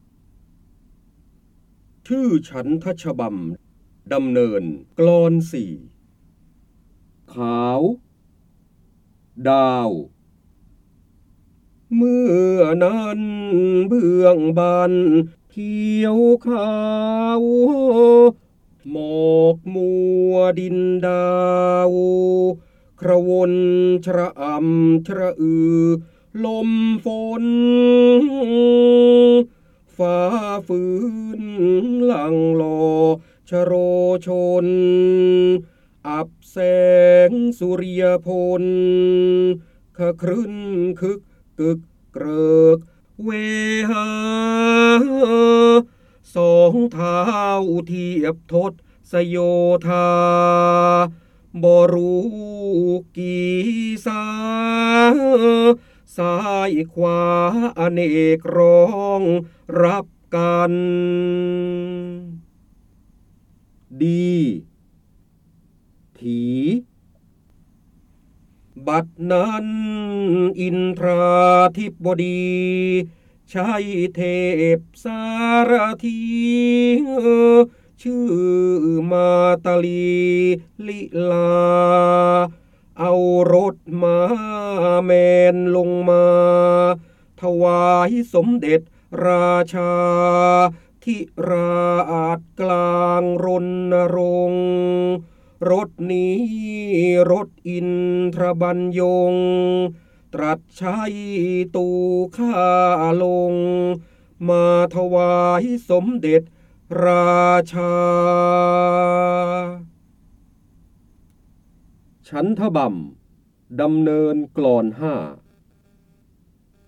เสียงบรรยายจากหนังสือ จินดามณี (พระโหราธิบดี) ฉันทฉบำดำเนอรกลอน ๔